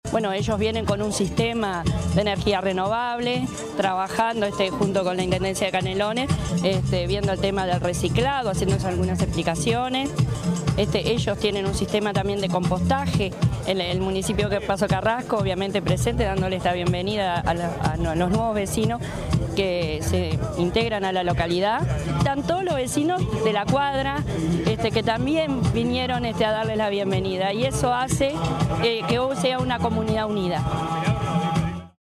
alcaldesa_de_paso_carrasco_veronica_veiga.mp3